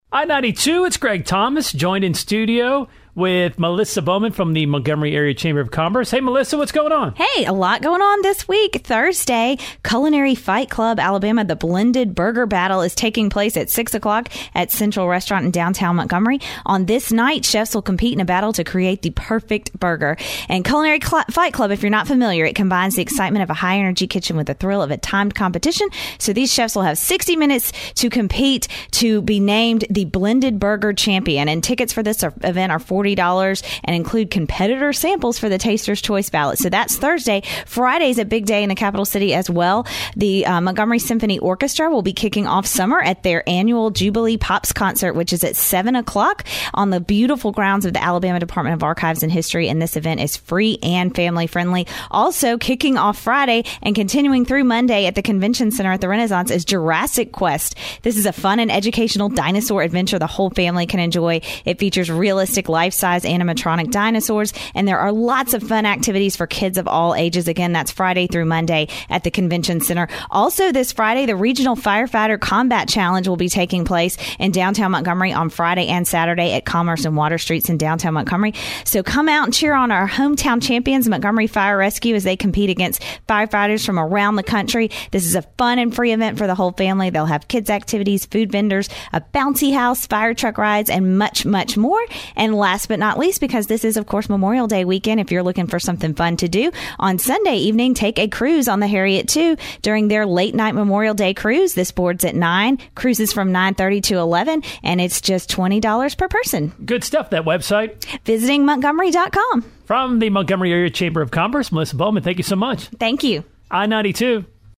in the I-92 WLWI studio